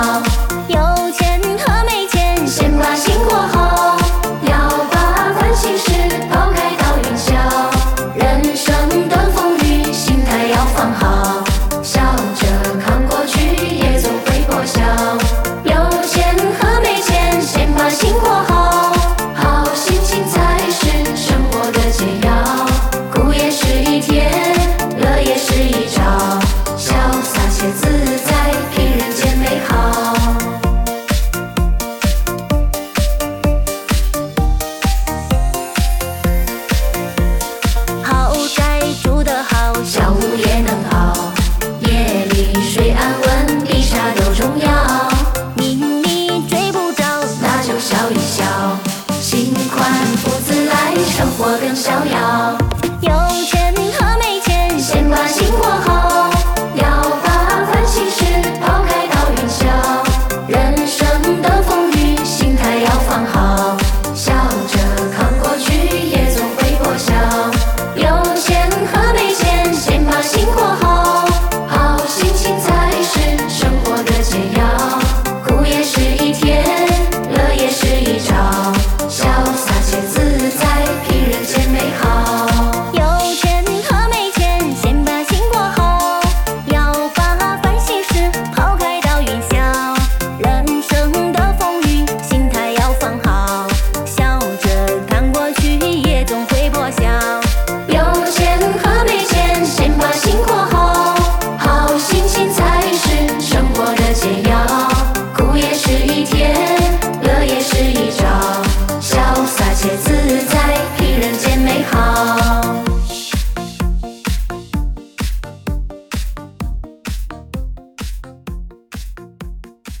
DJ版